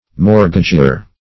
Mortgageor \Mort"gage*or\, Mortgagor \Mort"ga*gor\, n. (Law)
mortgageor.mp3